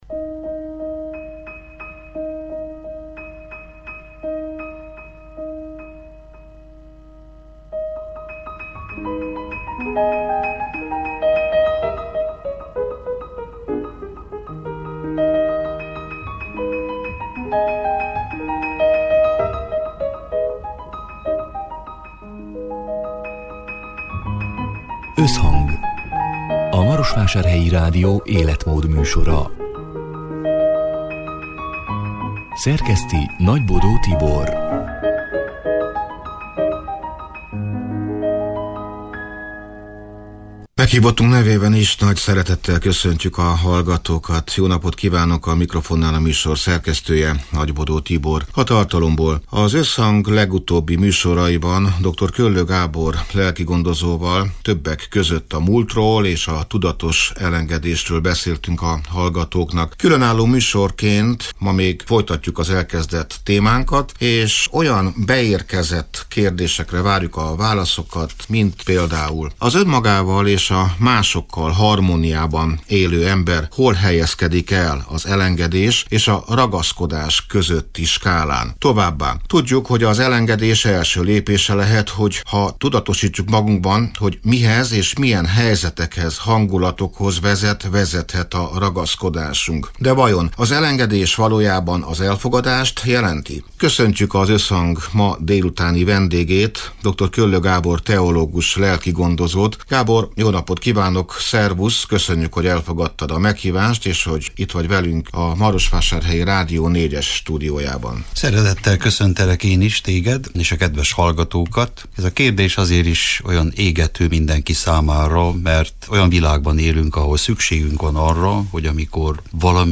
Hol van az egyensúly a ragaszkodás és az elengedés között? A soron következő Összhangban arról beszélgetünk, hogy miként találhat harmóniát az az ember, aki önmagához hű marad, de másokat sem akar birtokolni. Mit jelent valóban szabadnak lenni a kapcsolatainkban?